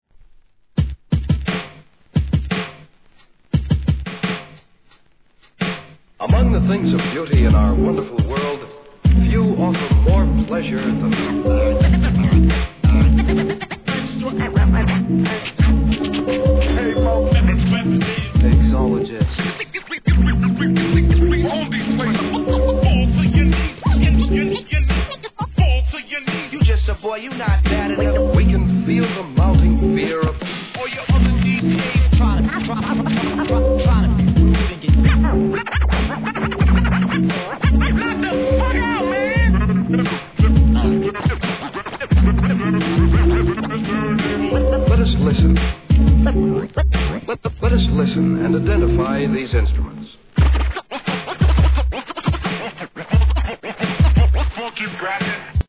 1. HIP HOP/R&B
MIDDLE～NEW SCHOOL CLASSICSオケ使いのメドレー、DOPEブレイク・ビーツ満載で重宝するでしょう!